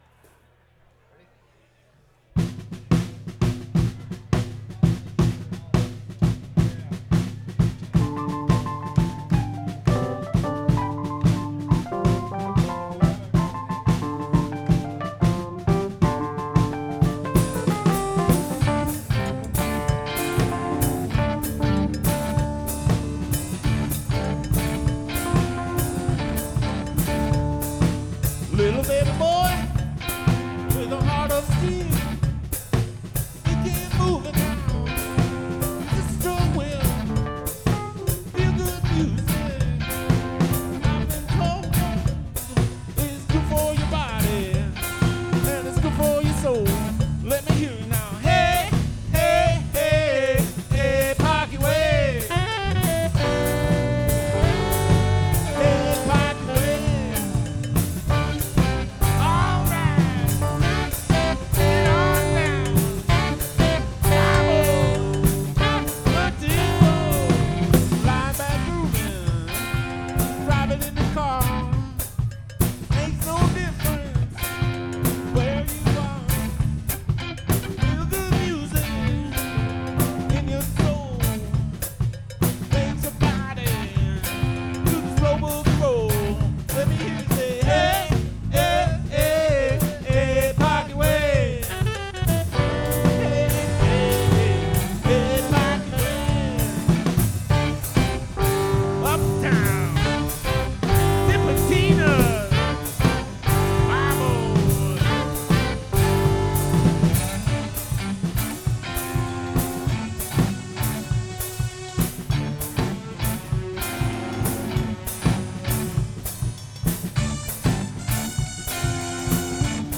by The Meters
Dm